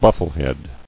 [buf·fle·head]